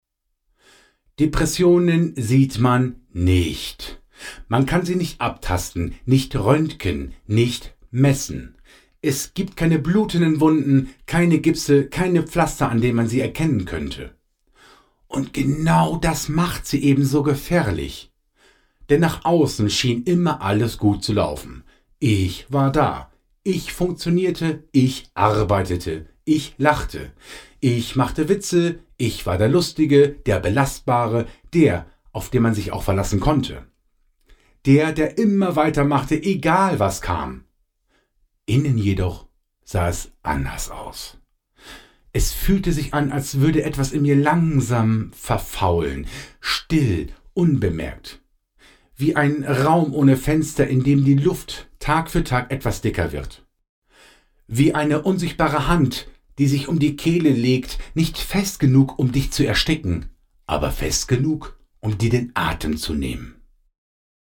Ob Hörbuch, Dokumentation oder Voice-Over: Mit Ruhe, Tiefe und Ausdruck wird aus Text eine Atmosphäre, die gehört und gefühlt werden kann.